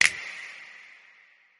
snare snap crank that.wav